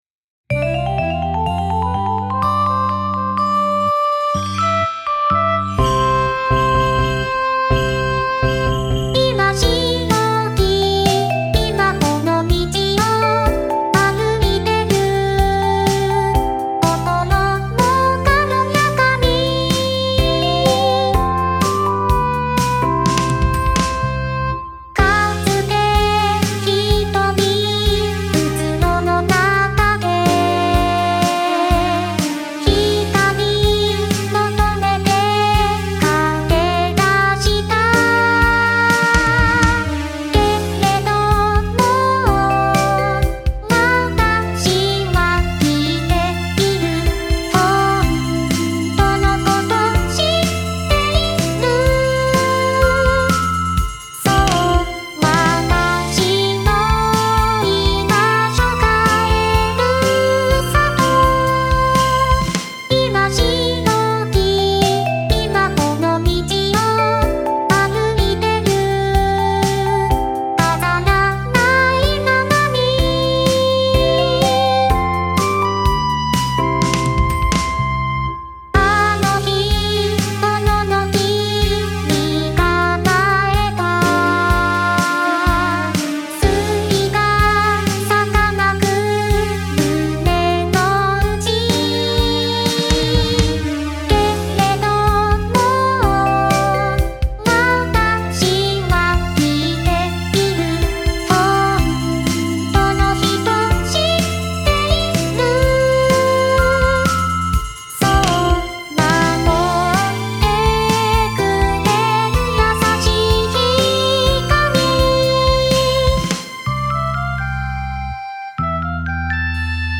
白き道（唄：初音ミク）